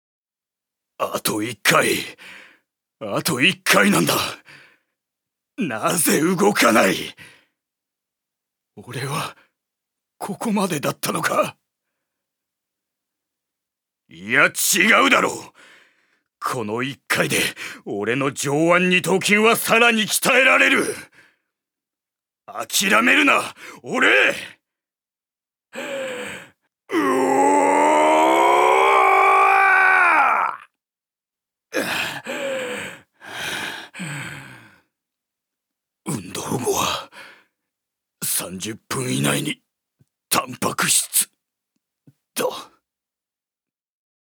所属：男性タレント
セリフ６